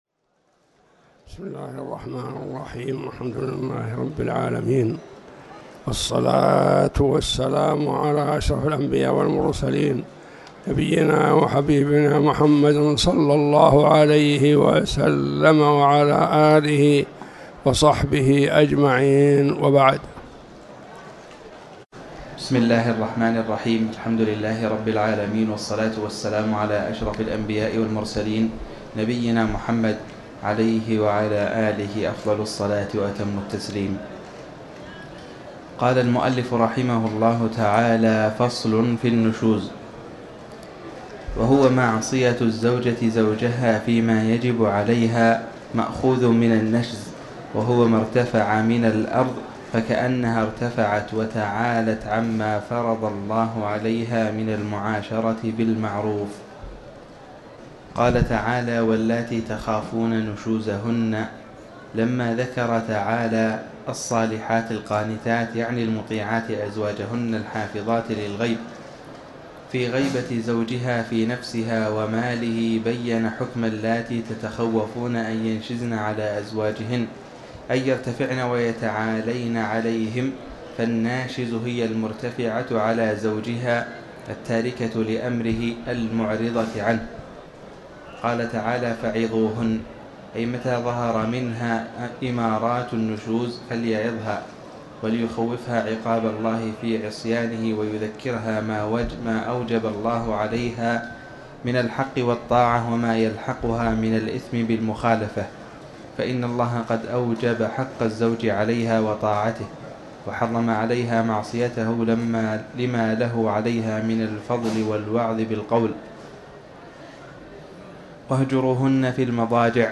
تاريخ النشر ١٧ ذو القعدة ١٤٤٠ هـ المكان: المسجد الحرام الشيخ